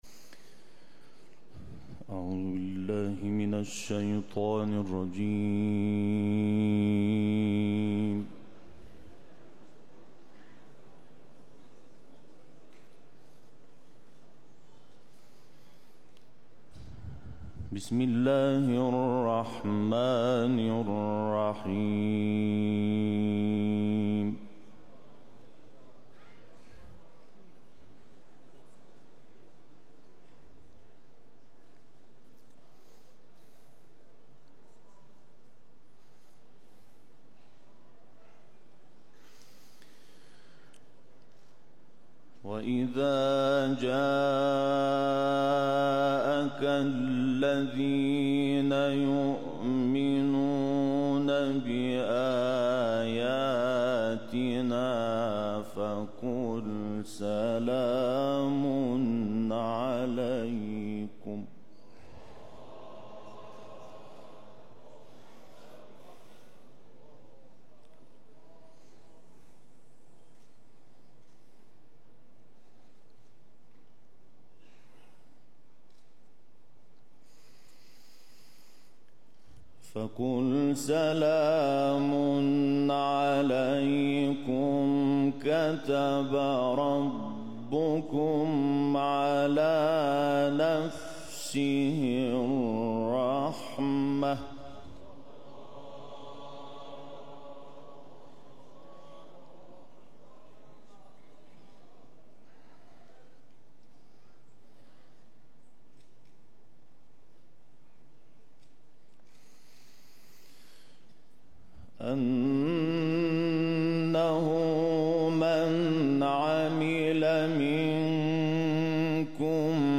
این جدیدترین و آخرین تلاوت استاد محمود شحات انور است که در حسینیه رهبری اجرا گردیده
تلاوت جدید استاد شیخ محمود شحات انور در مقابل رهبر مورخه 1394/3/2